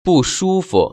[bùshū‧fu]